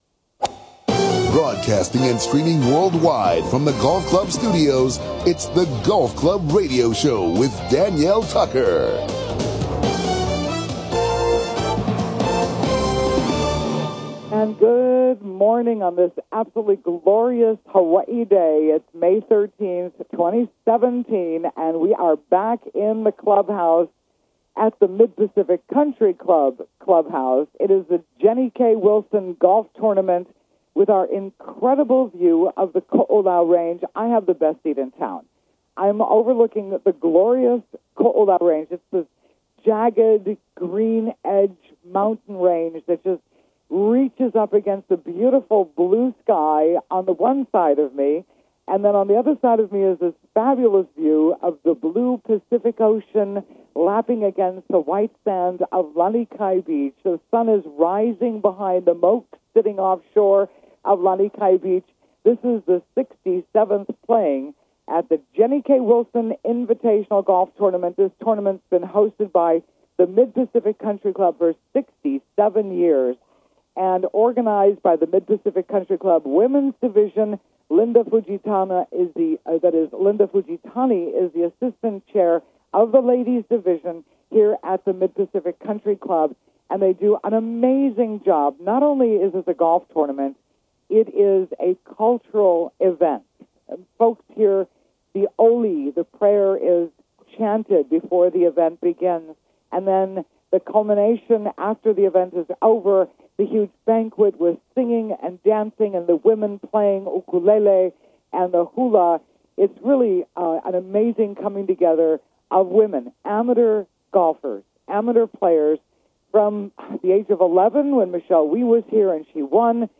Live remote from the 67th Jenny K. Wilson Invitational Mid Pacific Countryt Club Lanikai, Oahu, Hawaii